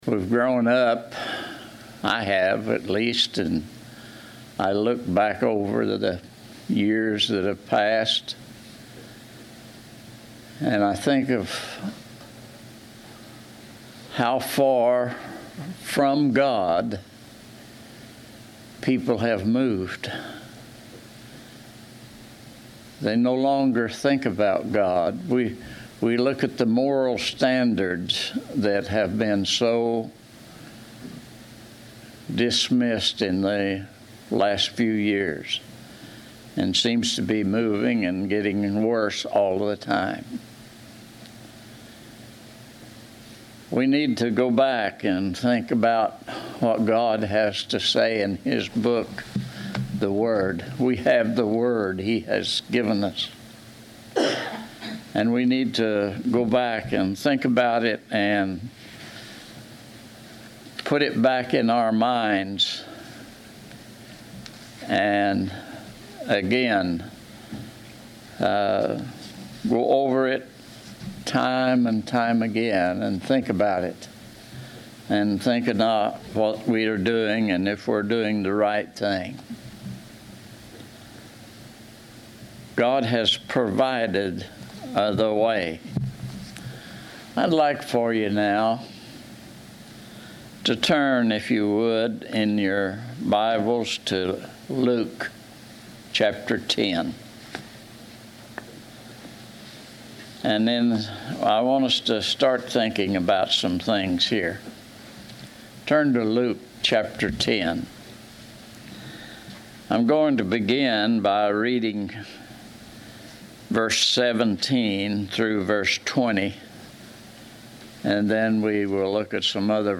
Service Type: Sunday 11:00 AM